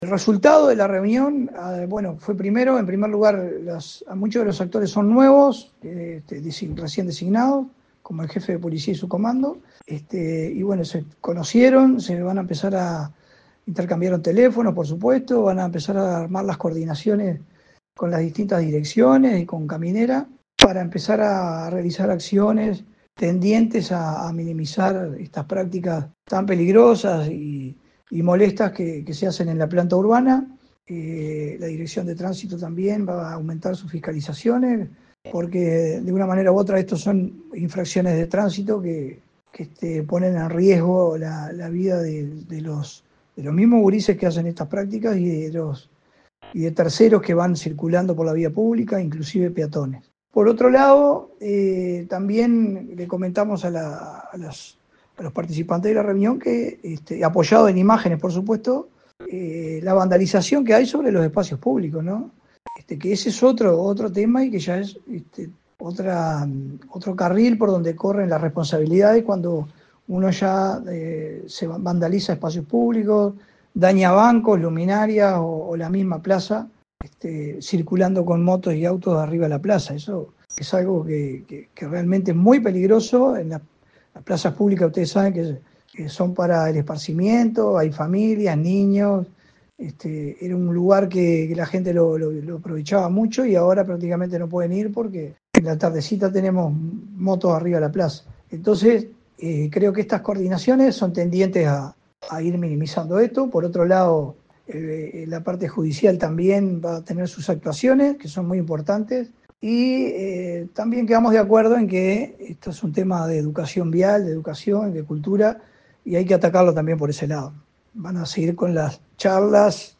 También se incentivará y reforzará acciones educativas en seguridad vial, indicó el alcalde Leonerdo Giménez.